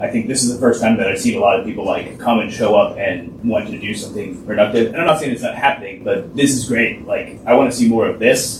One of the residents that attended Tuesday’s public meeting at City Hall said the turnout was great as it was but the number isn’t enough to represent the City’s entire population…